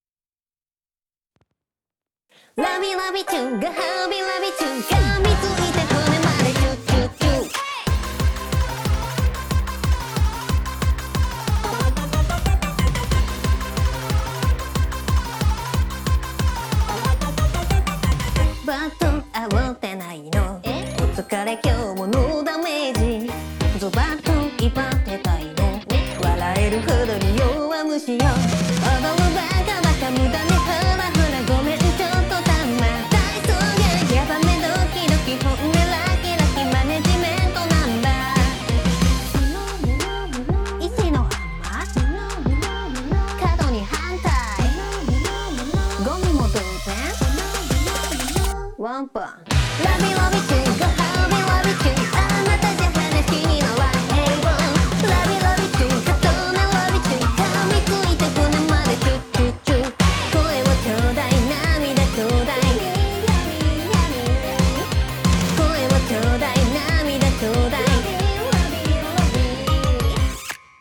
▼JJP Vocalsを使用してmixした音源
※先にStudio One付属のEQで低音をがっつりカットした後JJP Vocalsを使用しています。